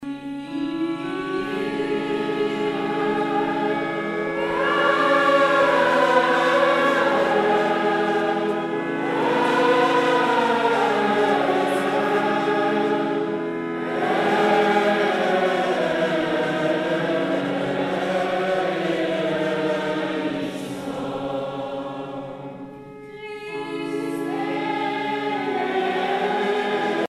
enfantine : prière, cantique
Pièce musicale éditée